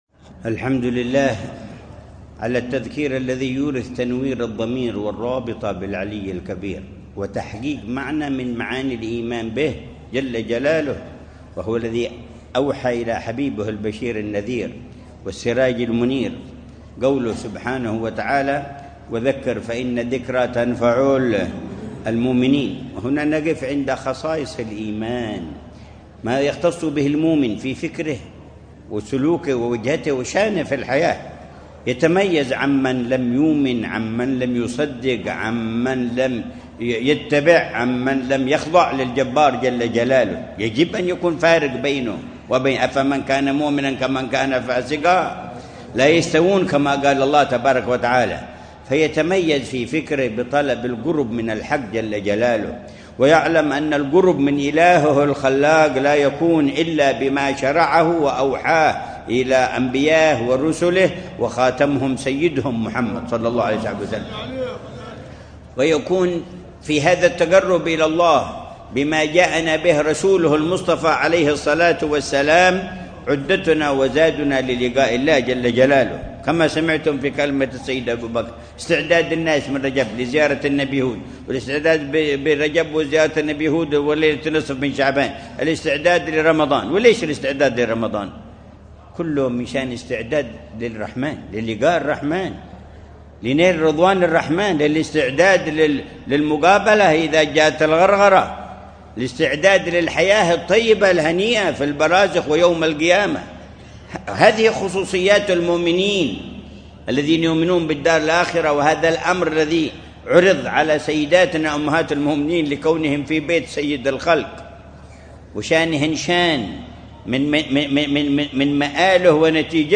مذاكرة
في مسجد الإمام حسين مولى خيلة ، بمدينة تريم